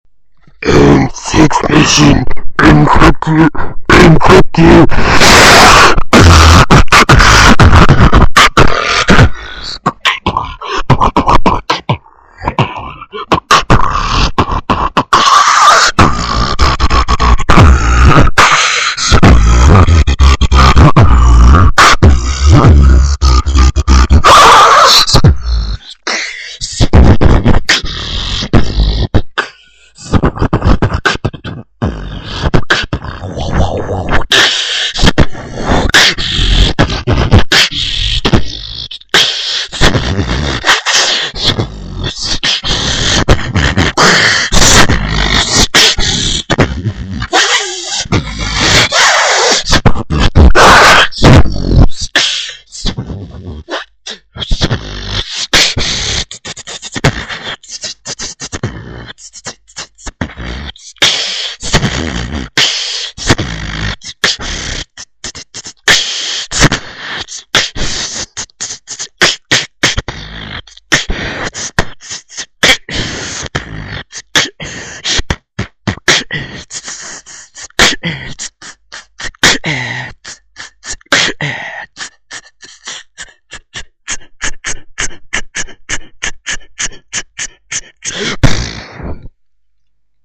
Выкладываем видео / аудио с битбоксом
Мой фристайл.
бит норм, но качество звука плоховатое